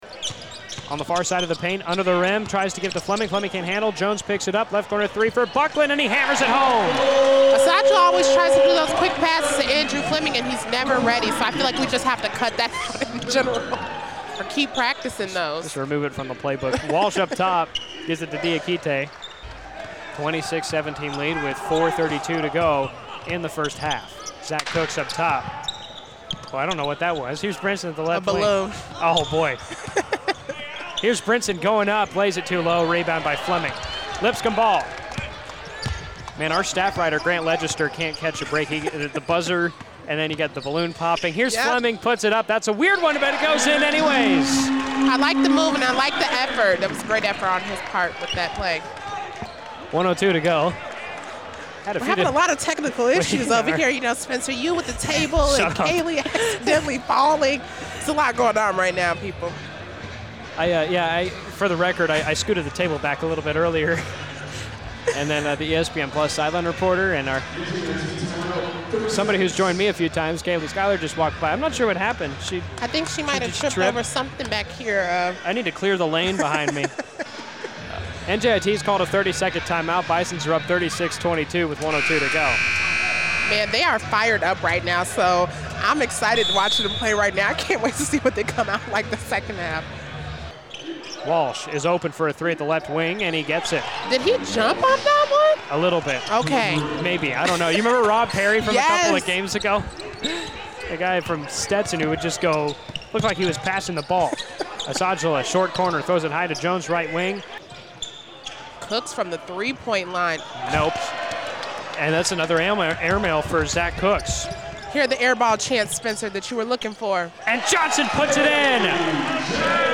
sports - radio broadcast clip